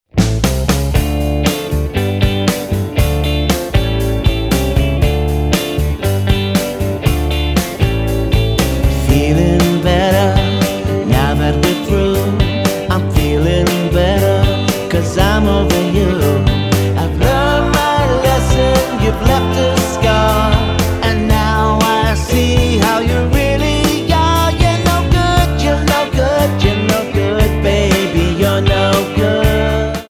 Tonart:Dm Multifile (kein Sofortdownload.
Die besten Playbacks Instrumentals und Karaoke Versionen .